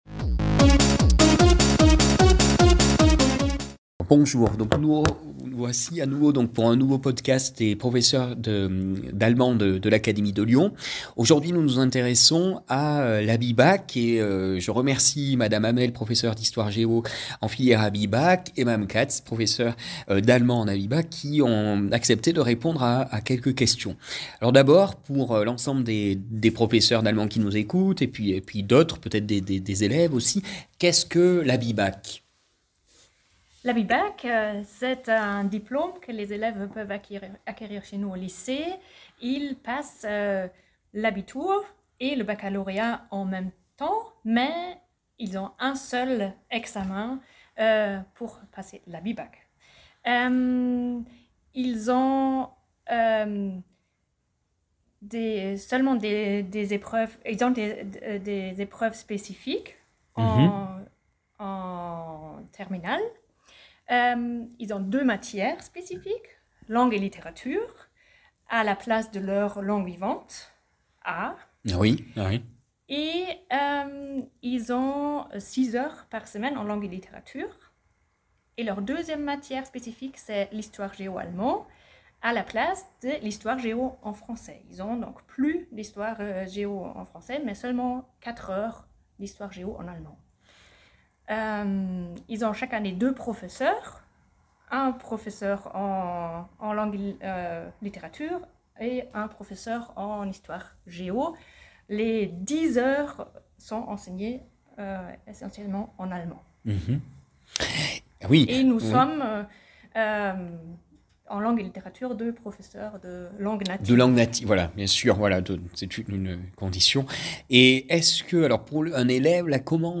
Podcasts thématiques l’ABIBAC au Lycée Jean-Perrin (Lyon)